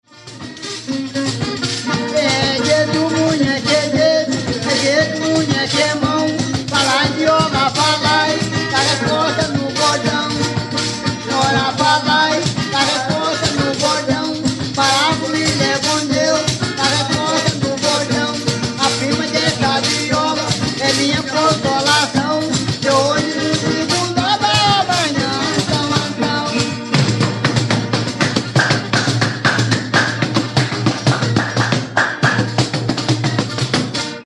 Dança de pares soltos com formação em fileiras que se defrontam registrada no município de Cabo Frio, RJ. Duas violas e um pandeiro fazem o acompanhamento musical, enquanto se desenvolve a coreografia: sapateado dos pares nas fileiras iniciais, evolução por fora e depois por dentro, retorno aos lugares primitivos, troca de pares, um a um, nas fileiras opostas e balanceio simultâneo dos demais em seus lugares.